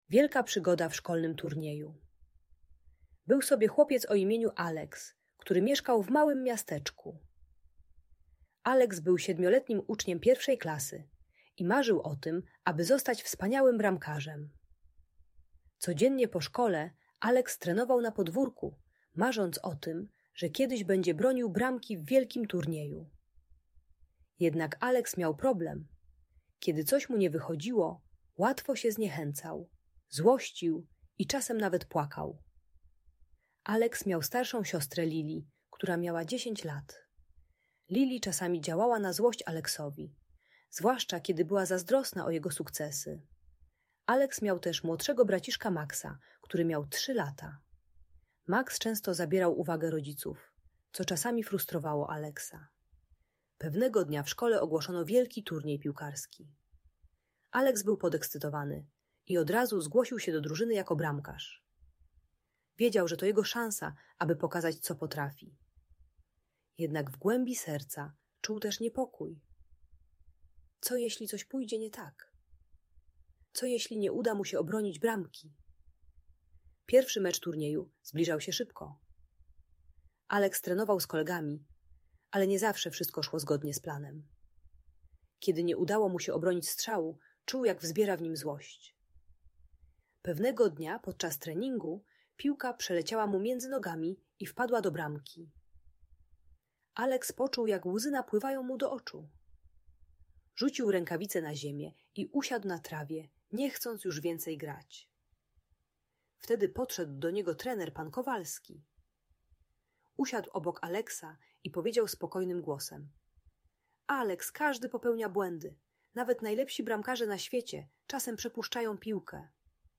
Wielka Przygoda Alexa - Bunt i wybuchy złości | Audiobajka